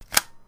shotgun_put_ammo.wav